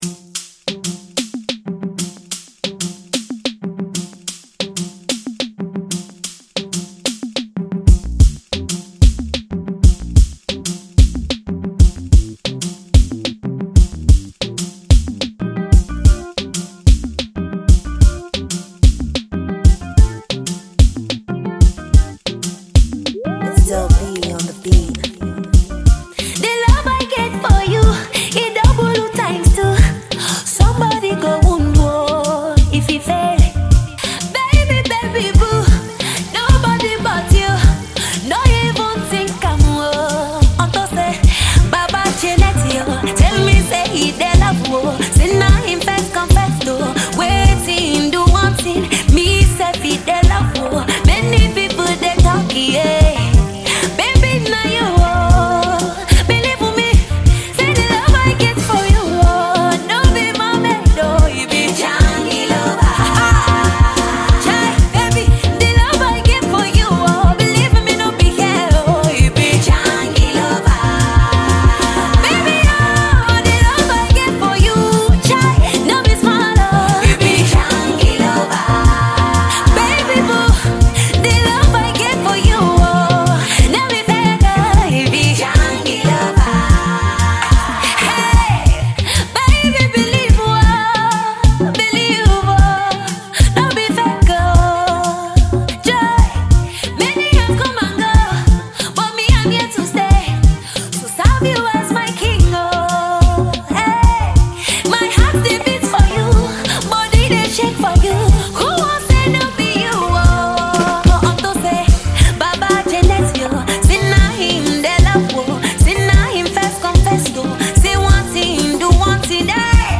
Pop
Highlife song